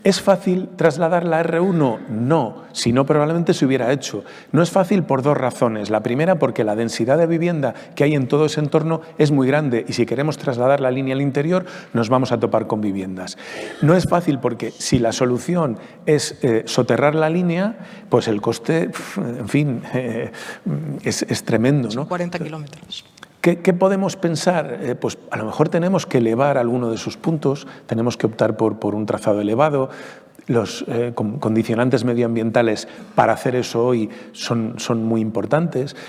En aquest context, Puente ha apuntat la possibilitat d’elevar alguns trams de la línia. Ho ha dit en un col·loqui amb periodistes a l’Ateneu de Madrid.